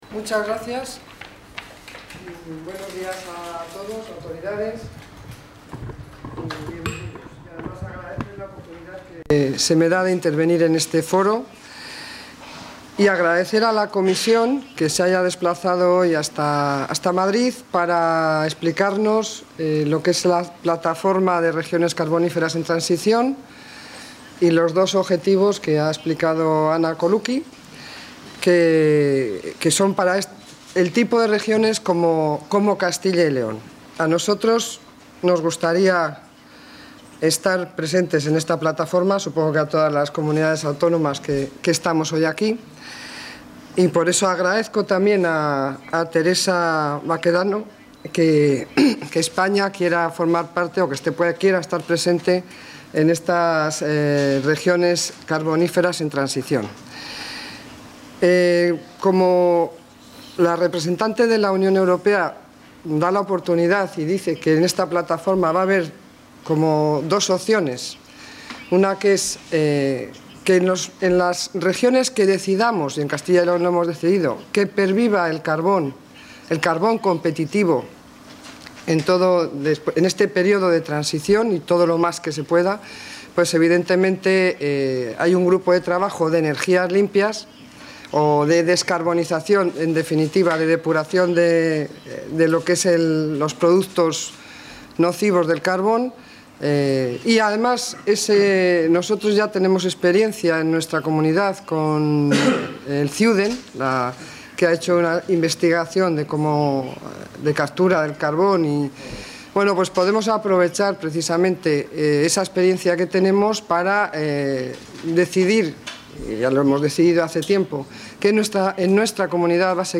A la reunión celebrada en la sede de la Comisión Europea en Madrid asistió también la directora general de Política Energética y Minas del Ministerio de Energía, María Teresa Baquedano; la comisionada del Plan de Dinamización Económica de los Municipios Mineros de Castilla y León, Ana Luisa Durán; el director general de Energía y Minas del Gobierno de Aragón, Alfonso Gómez; el consejero de Empleo, Industria y Turismo de Asturias, Isaac Pola; y el director general de Industria, Energía y Minería de Castilla-La Mancha, José Luis Cabezas.
Intervención de la consejera de Economia y Hacienda Plataforma de las regiones mineras en transición